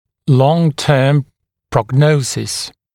[ˌlɔŋ’tɜːm prɔg’nəusɪs][лон-тё:м прог’ноусис]долгосрочный прогноз